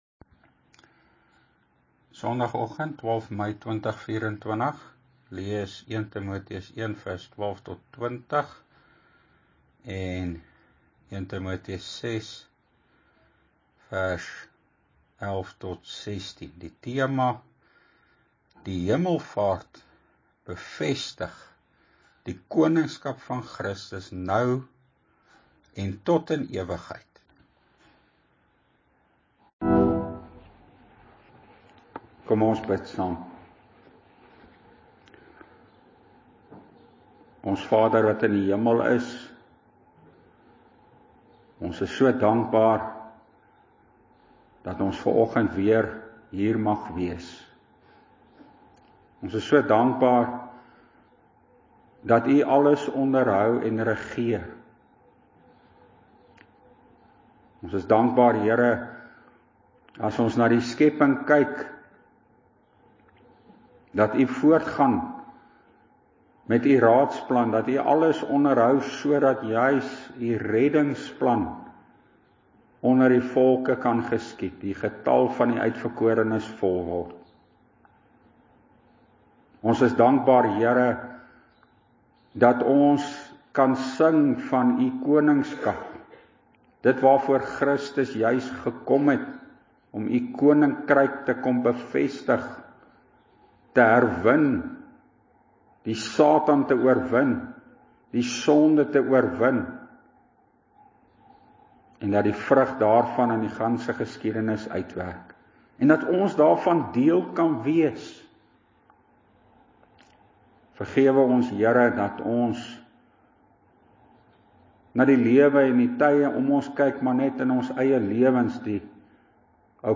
PREEK: Die Koningskap van Christus (1 Tim. 1:17; 6:15)